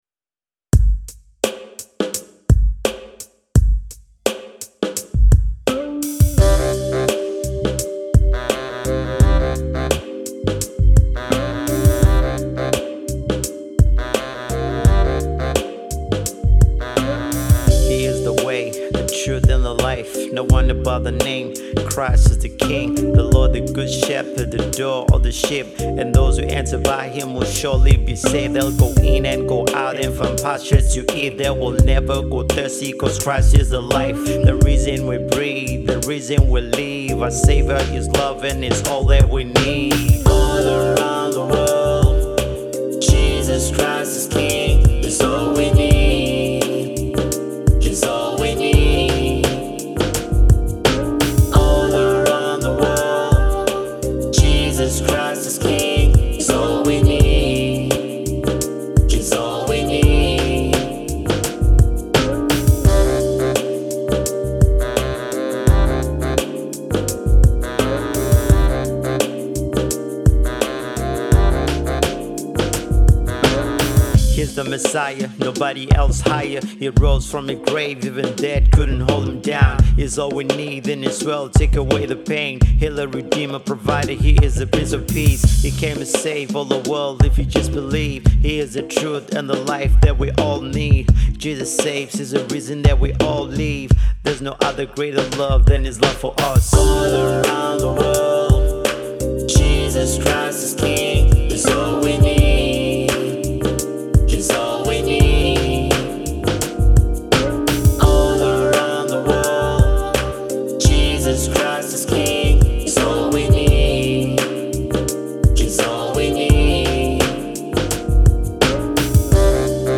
Gospel act
vibrant new age gospel music band